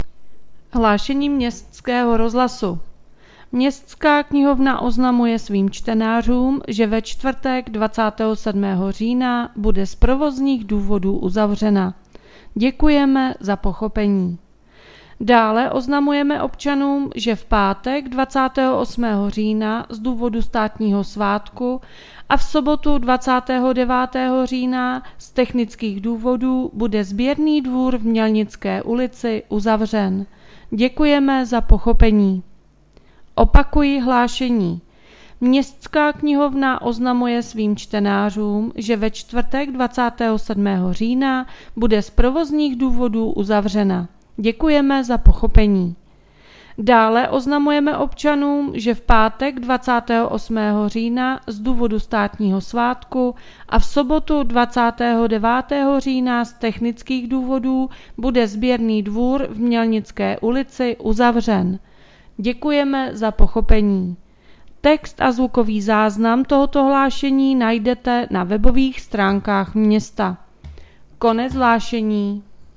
Hlášení městského úřadu 24.10.2022